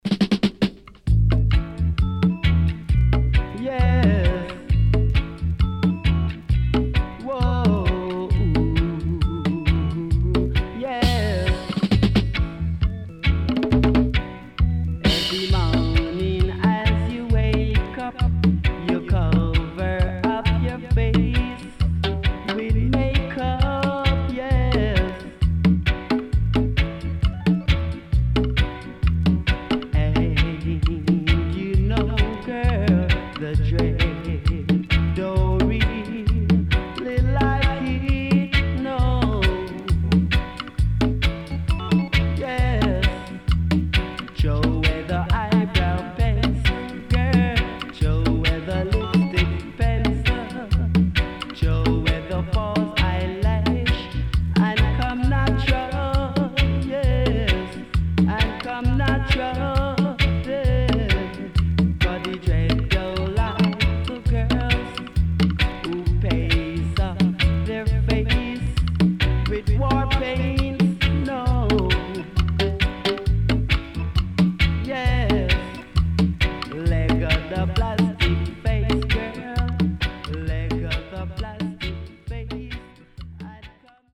CONVERSATION.Nice Vocal & Dubwise